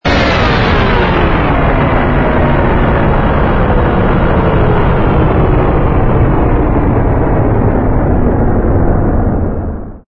turbine_landing.wav